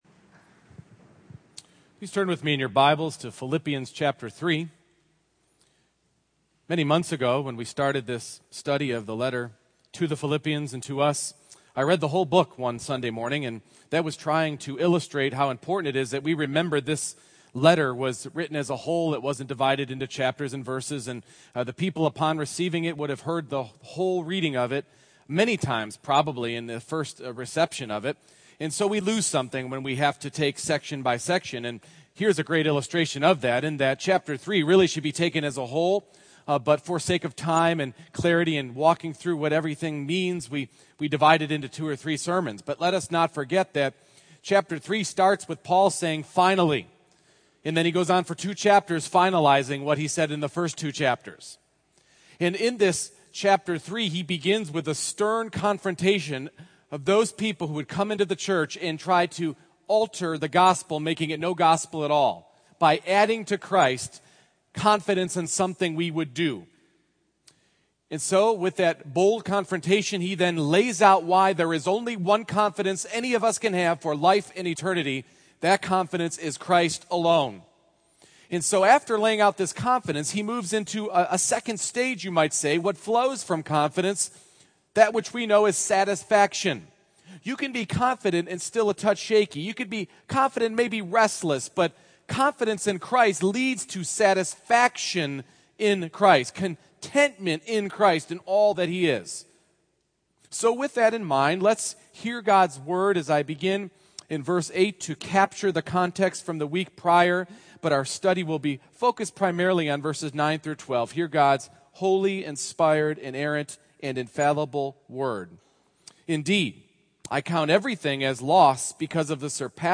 Philippians 3:9-12 Service Type: Morning Worship I. Satisfied to be “found” in Christ